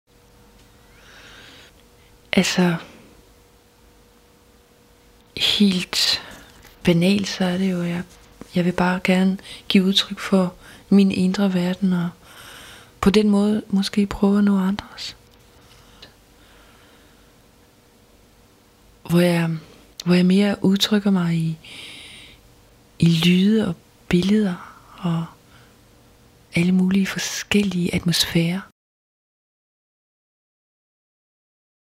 Soundbites for use in radio
• Download interview sound bites, raw, without music: